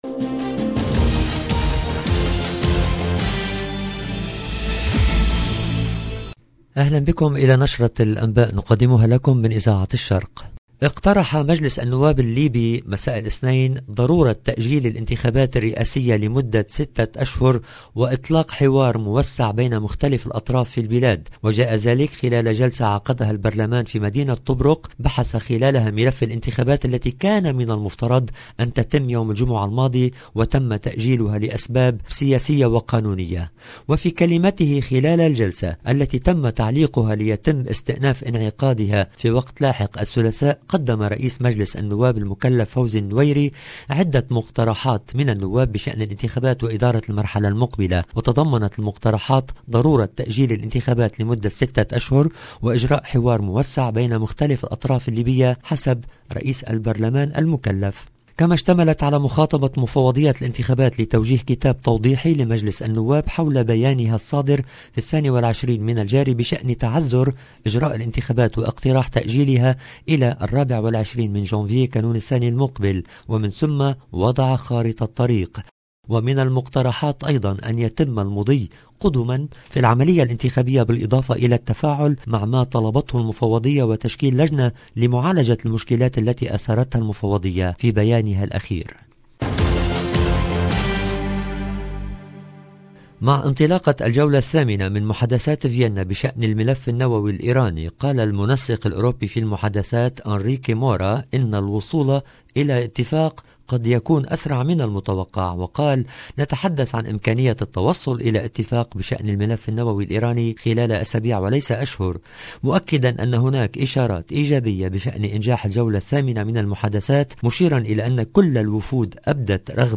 LE JOURNAL DU SOIR EN LANGUE ARABE du 28/01/21